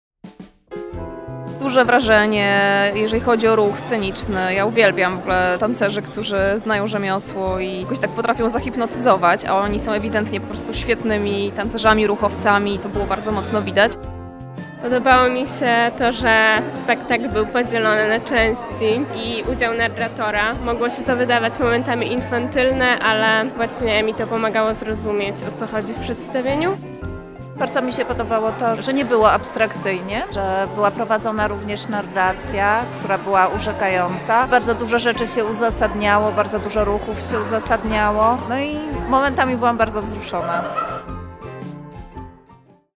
Przygotowaliśmy dla was krótką relację z wydarzenia: